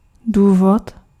Ääntäminen
US : IPA : [ˈmæɾ.ɚ]